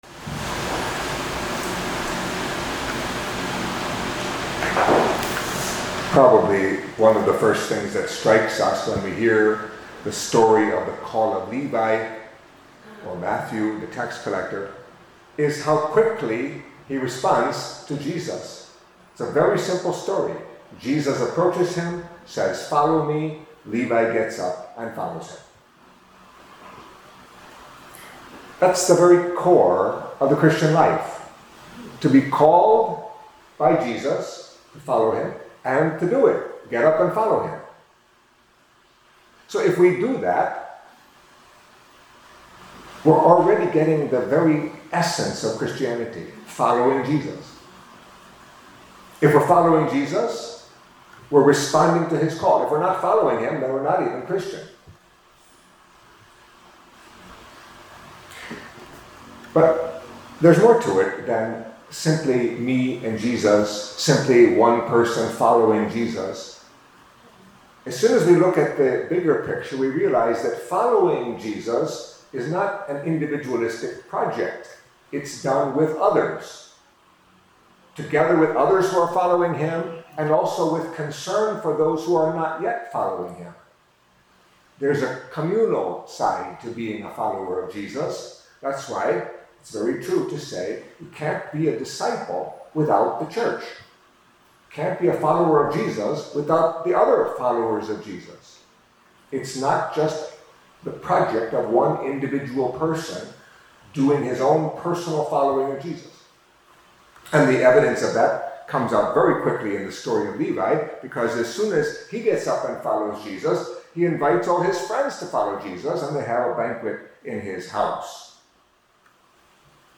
Catholic Mass homily for Saturday of the First Week in Ordinary Time